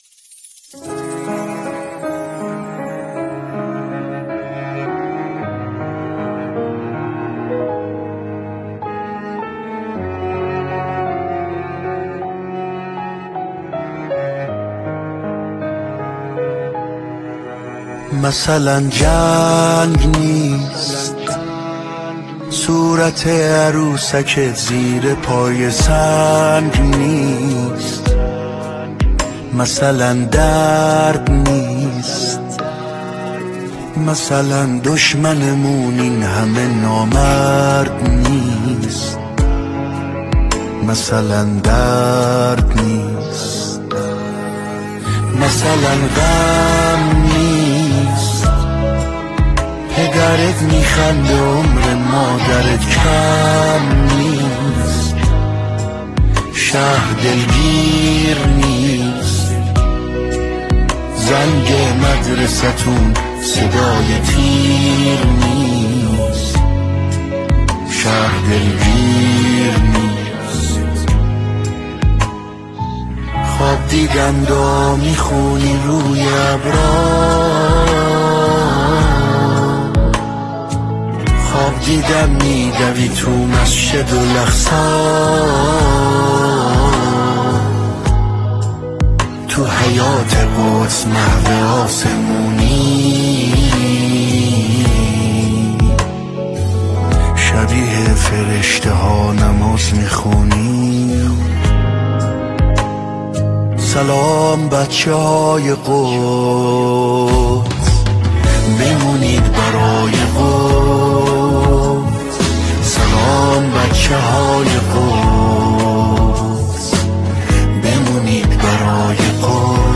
حماسی و ارزشی